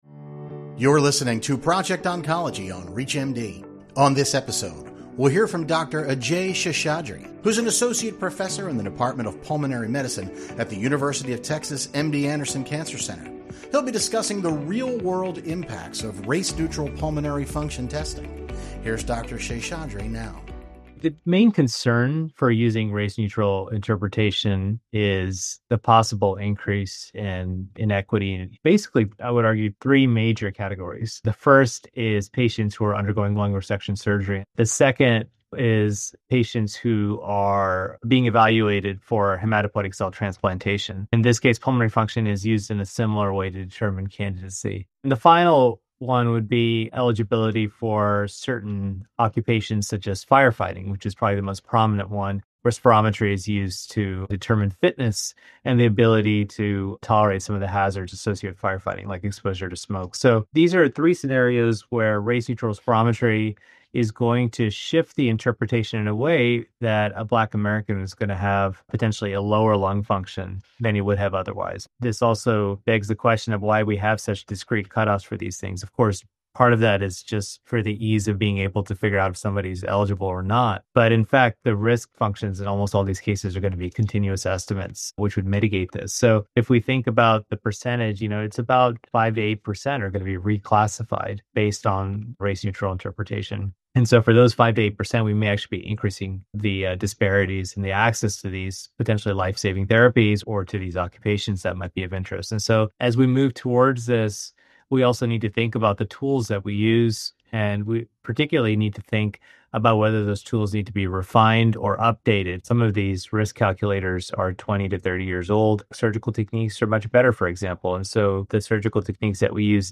Showcasing major breakthroughs in cancer care from worldwide clinical trials, Project Oncology® educates and assists the healthcare professionals who dedicate their lives to helping patients fight cancer. Here you’ll find leading oncologists discuss and share essential cancer care strategies for all different types of cancer.